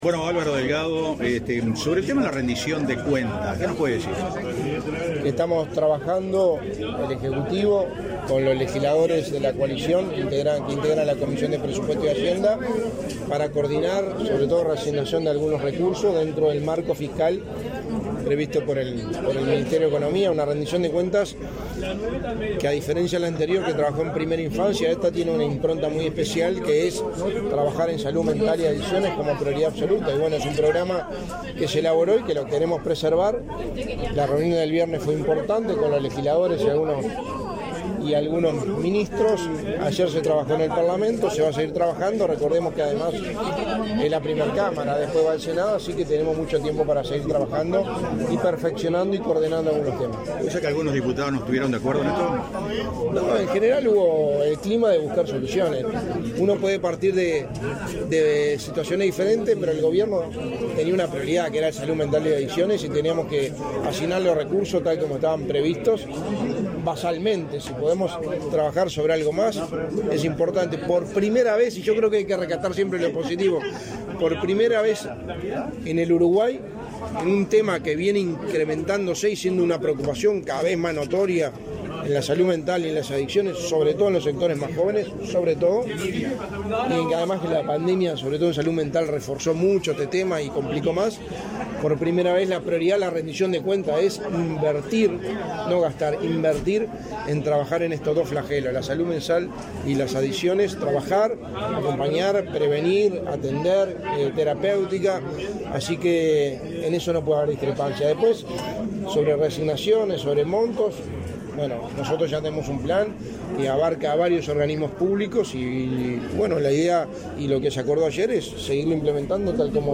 Declaraciones a la prensa del secretario de la Presidencia, Álvaro Delgado, en Nueva Helvecia
Declaraciones a la prensa del secretario de la Presidencia, Álvaro Delgado, en Nueva Helvecia 13/08/2023 Compartir Facebook X Copiar enlace WhatsApp LinkedIn Tras participar en el aniversario de la ciudad de Nueva Helvecia, este 13 de agosto, el secretario de la Presidencia de la República, Álvaro Delgado, realizó declaraciones a la prensa sobre temas de actualidad.